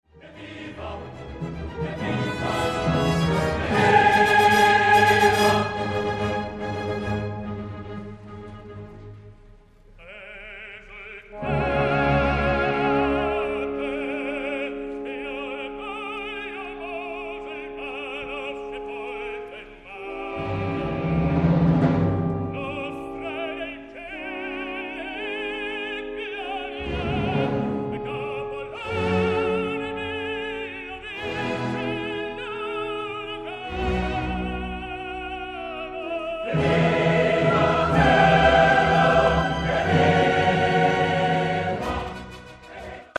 : I brani qui presentati sono tutti tratti da  registrazioni amatoriali , spesso realizzate da amici o colleghi Ne  è vietata la divulgazione con qualsiasi mezzo o utilizzo a  scopo commerciale.
Here below you can find some arias performed by  tenor  Antonello  Palombi.